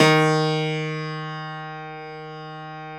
53f-pno07-D1.wav